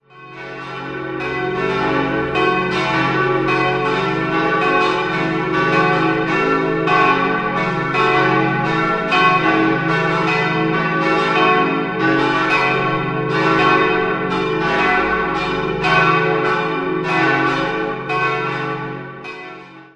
Idealquartett: cis'-e'-fis'-a' Alle Glocken wurden 1955 beim Bochumer Verein für Gussstahlfabrikation in V7-Rippe gegossen.